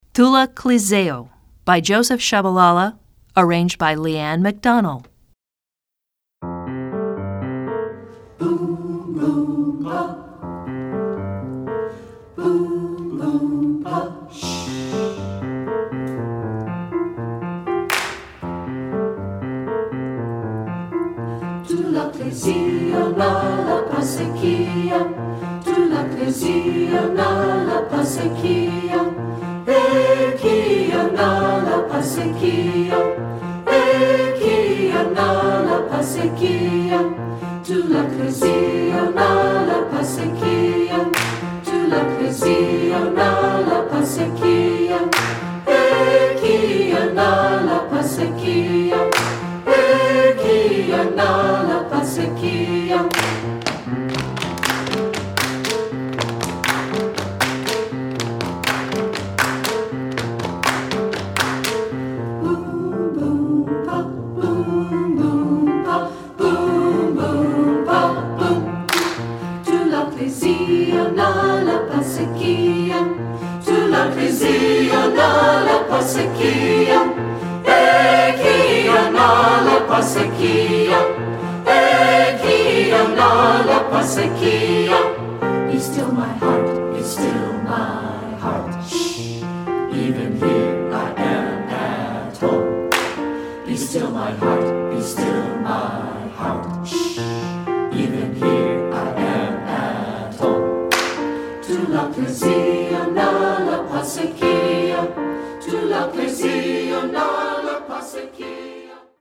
Choral Multicultural
SAB